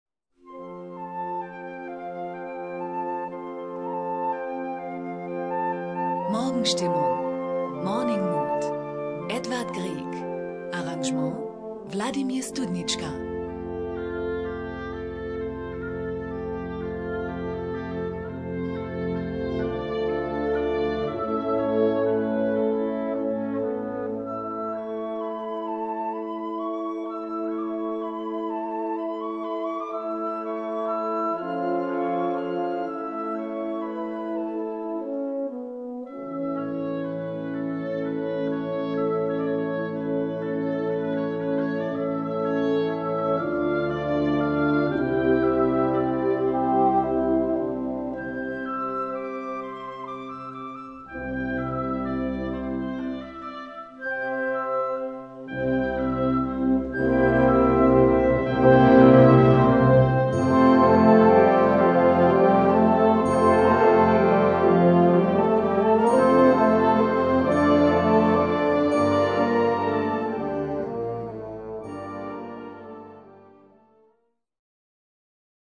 Gattung: Konzertante Blasmusik
Besetzung: Blasorchester
welche hier in einer Bearbeitung für Blasorchester erklingt.